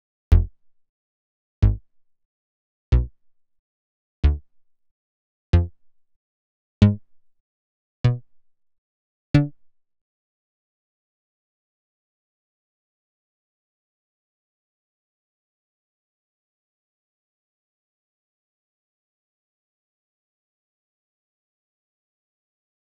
35_MoogAttackBass_D+3_1-2.wav